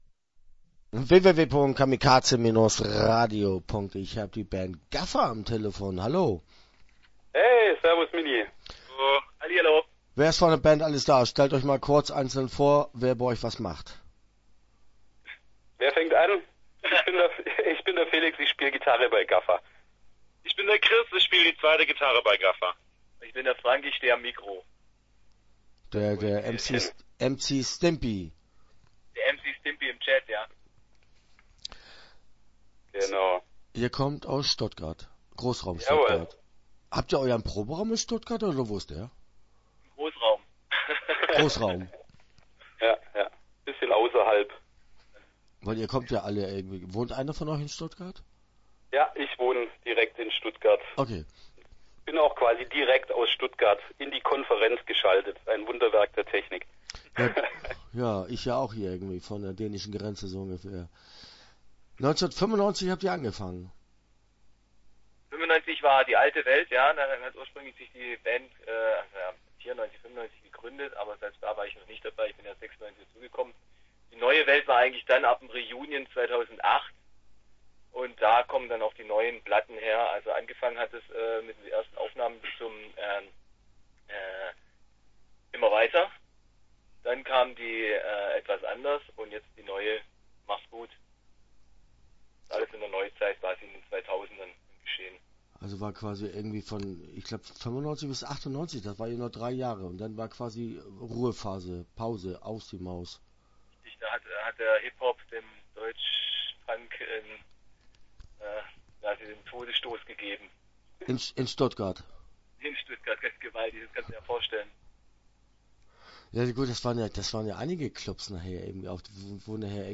Start » Interviews » GAFFA